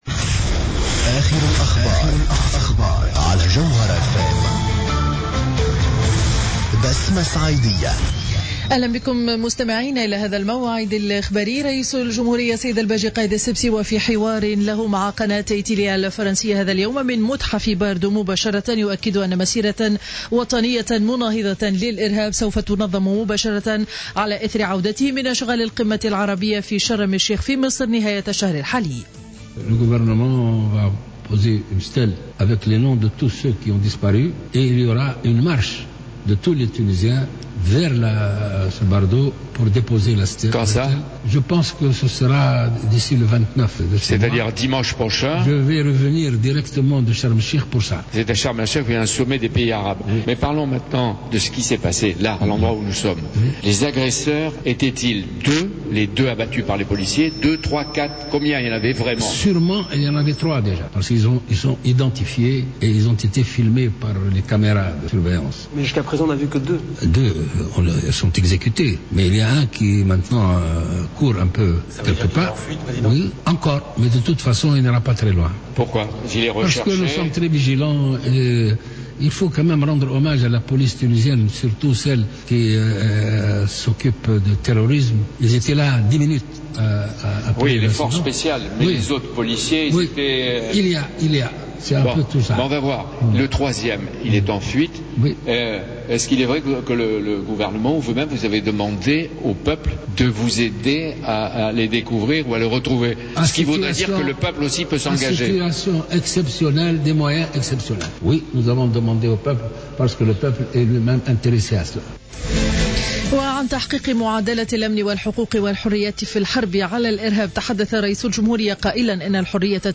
نشرة أخبار منتصف النهار ليوم الأحد 22 مارس 2015